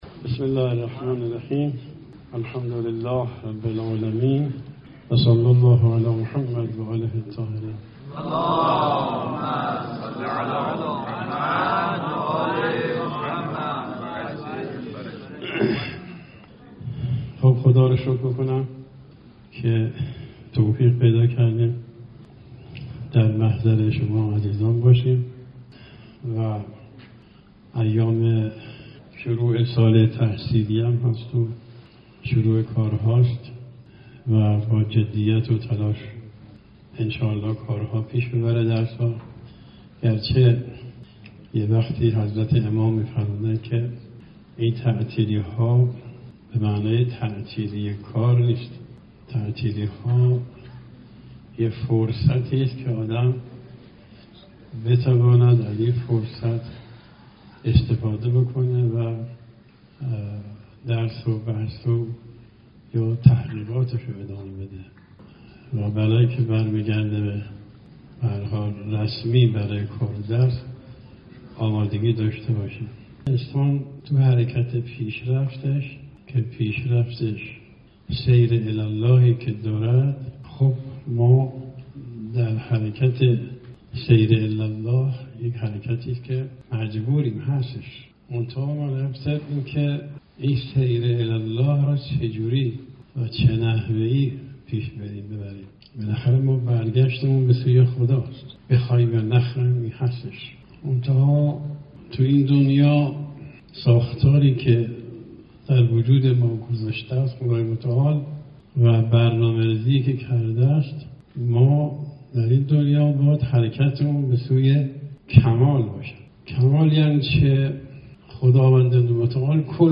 صوت | درس اخلاق آیت الله ناصری در مدرسه علمیه خان